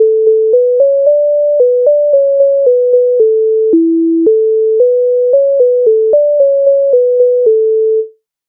MIDI файл завантажено в тональності A-dur
Ой котився кубар Українська народна пісня з обробок Леонтовича с. 146
Ukrainska_narodna_pisnia_Oj_kotyvsia_kubar.mp3